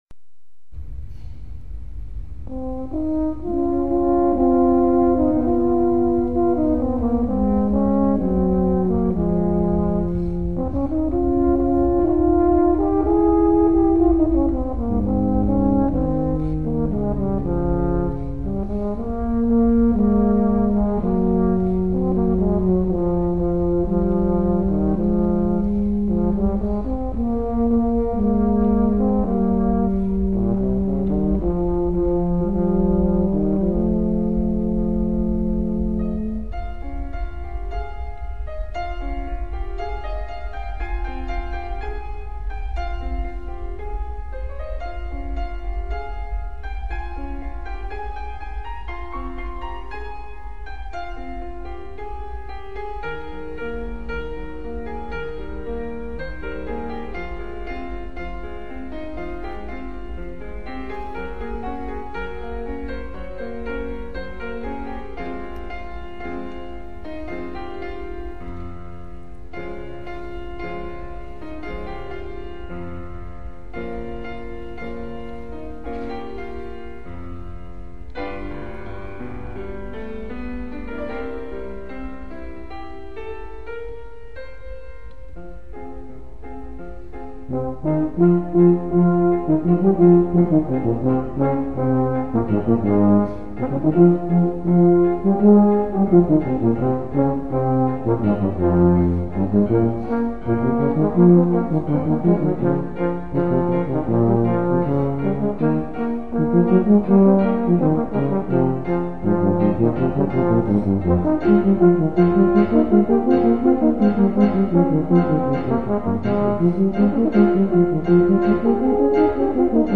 For Euphonium Duet
Arranged by . with Piano.